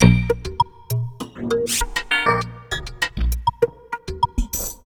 66 ARP LP -L.wav